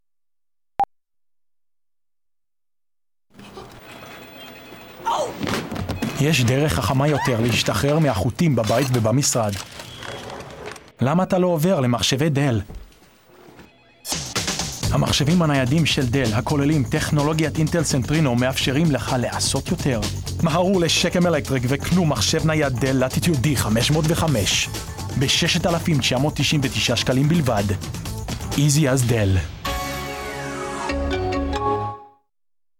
Actor, youthful rough diamond.
Cables Hebrew vers Shekem Electric Commercial